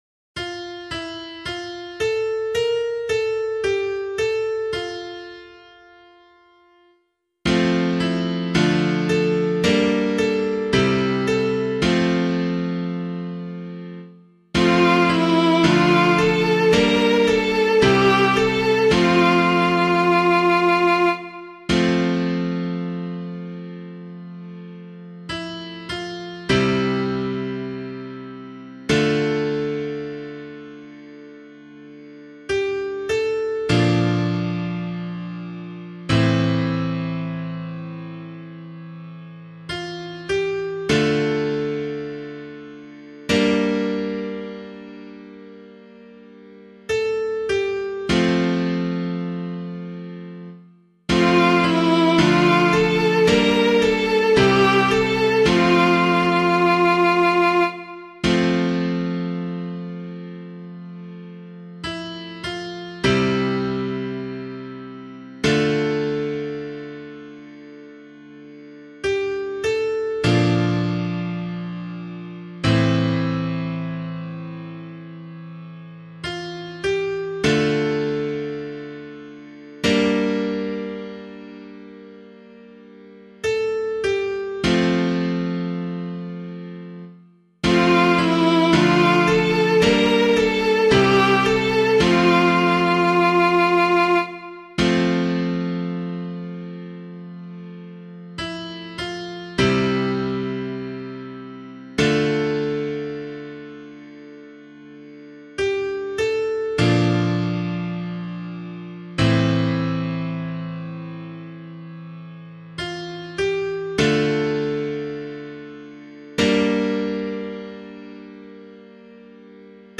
016 Lent 4 Psalm C [LiturgyShare 6 - Oz] - piano.mp3